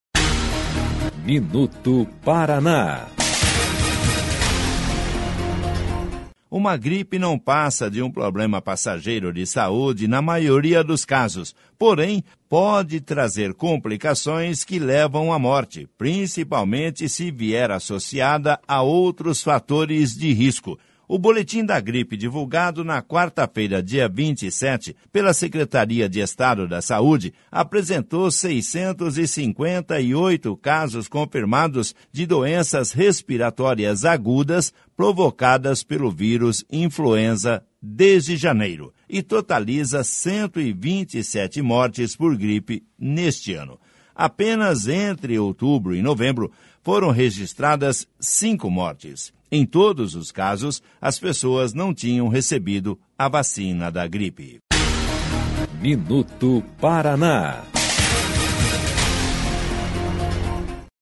MINUTO PARANÁ - BOLETIM DA GRIPE 27/11/19